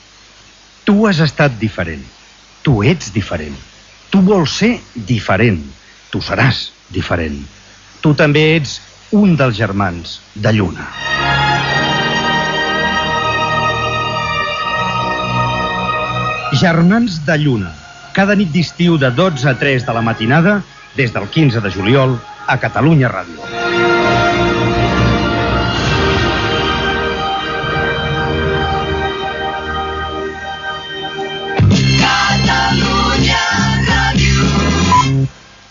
Anunci de l'inici del programa "Germans de lluna" i indicatiu de l'emissora
FM